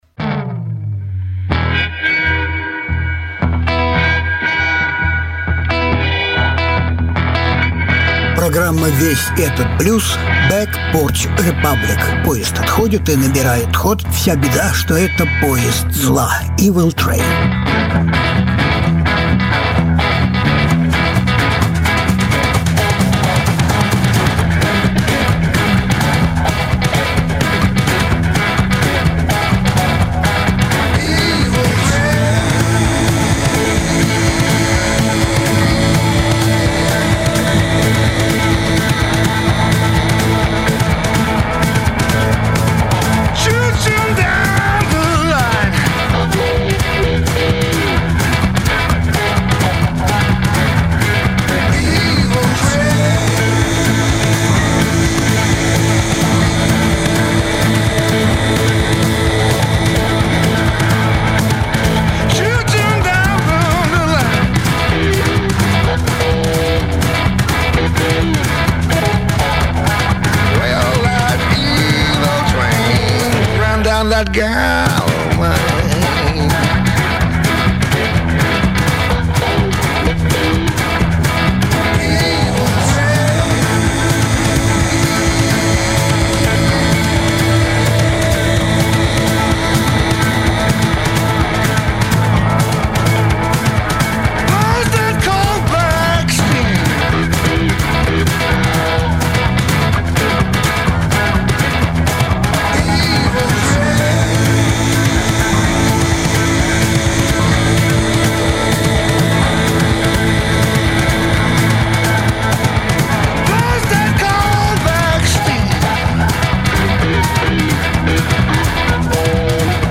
Различные альбомы Жанр: Блюз СОДЕРЖАНИЕ 25.05.2020 Норвежский гитарист